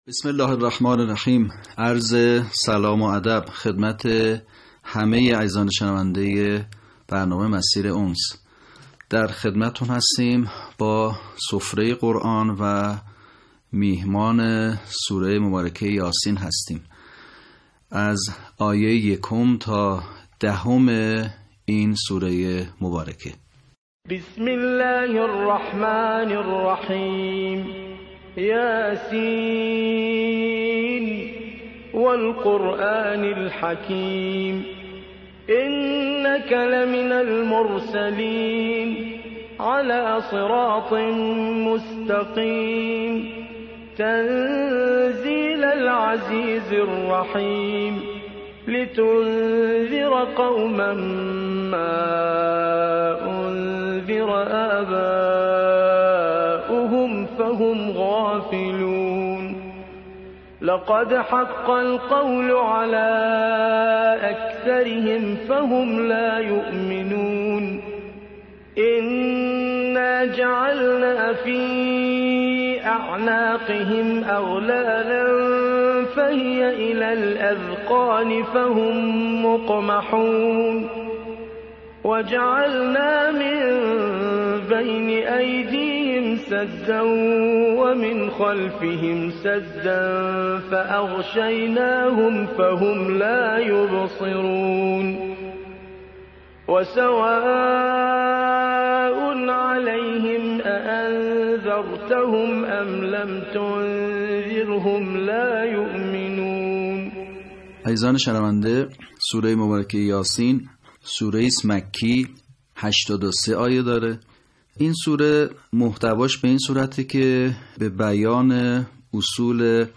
صوت | آموزش صحیح‌خوانی سوره یس